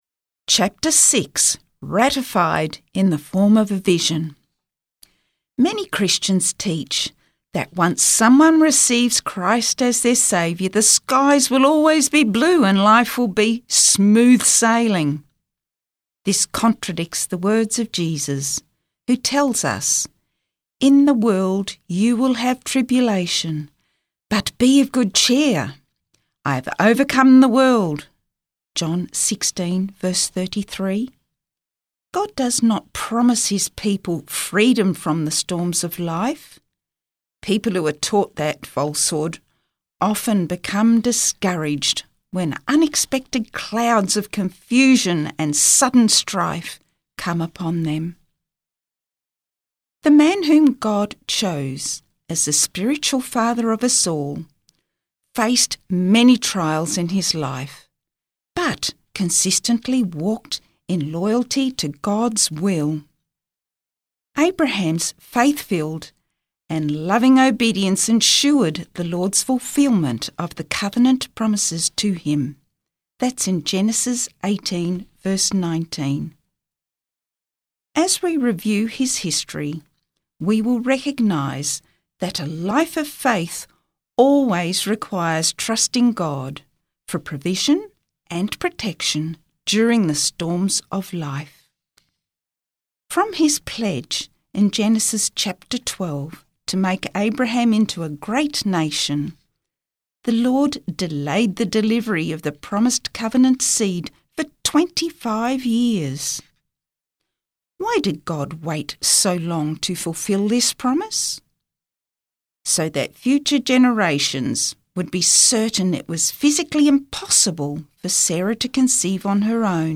Chapter 6 - Ratified In The Form Of A Vision Book Reading - Spotless podcast